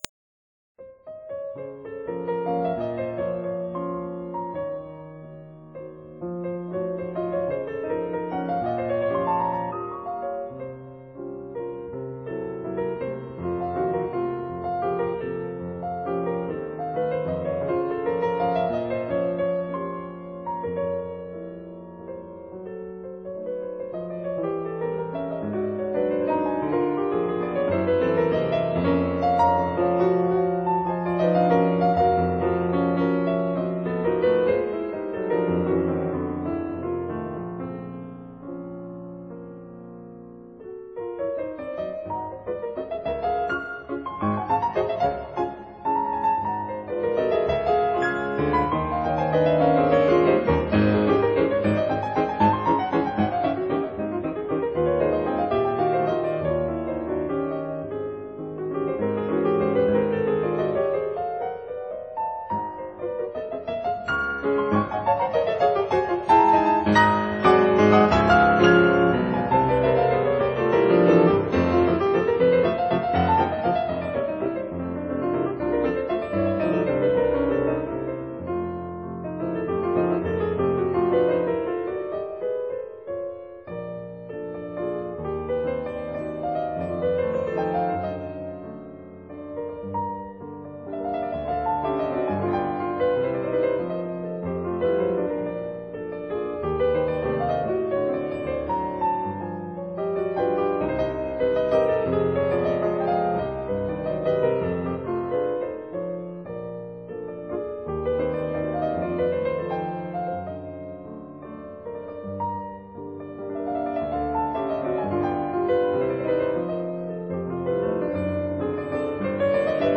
Molto moderato
Allegro impetuoso